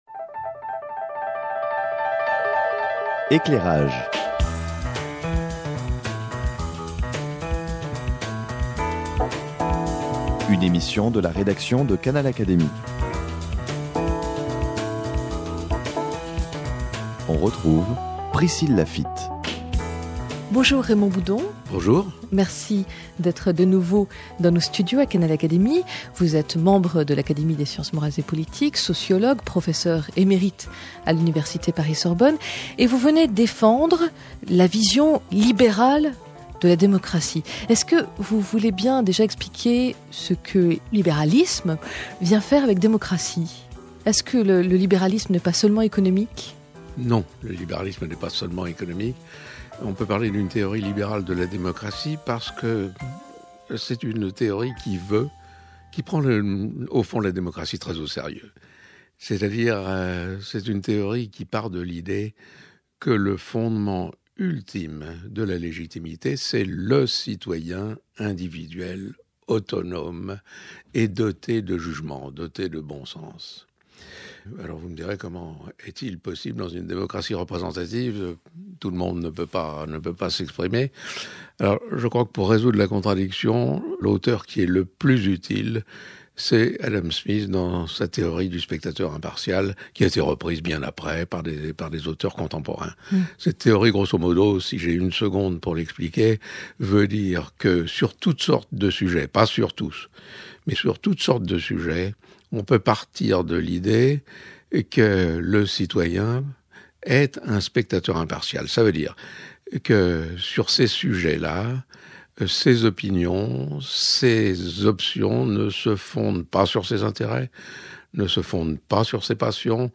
Le spectateur impartial, expliqué par Raymond Boudon
Pourquoi défendre le principe du spectateur impartial ? Parce que, selon Raymond Boudon, la démocratie représentative est mise à mal par la politique spectacle et par la démocratie participative. Raymond Boudon est sociologue, professeur émérite à l’Université Paris-Sorbonne, et membre de l’Académie des sciences morales et politiques.